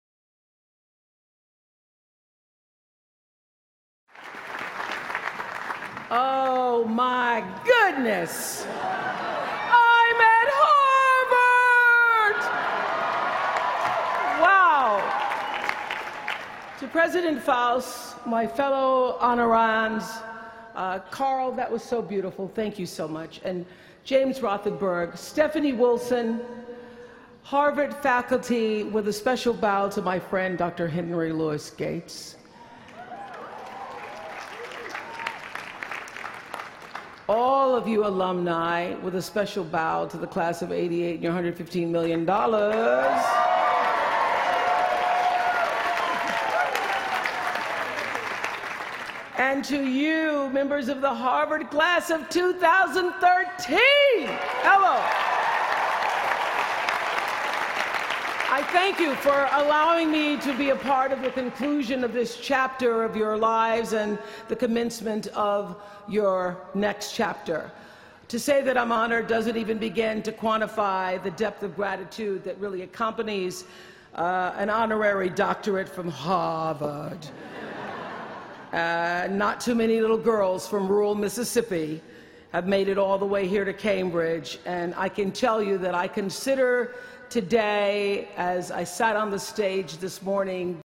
公众人物毕业演讲第352期:奥普拉2013在哈佛大学(1) 听力文件下载—在线英语听力室